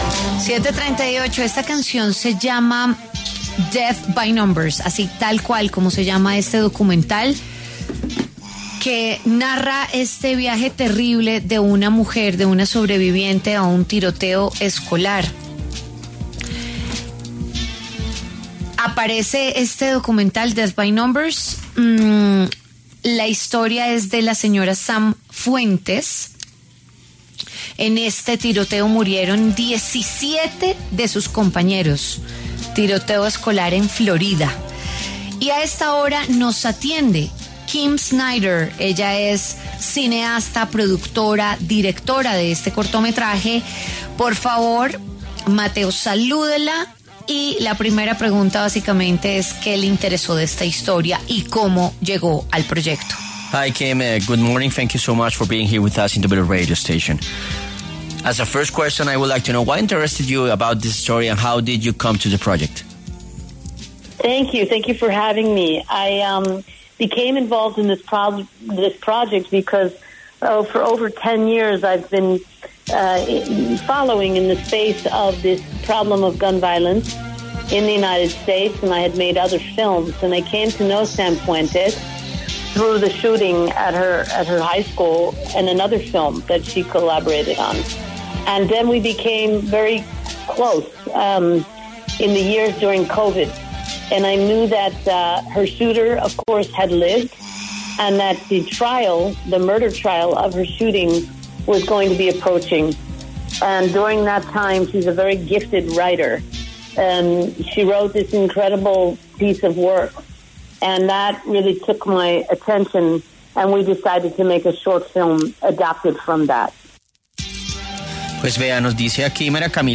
cineasta y productora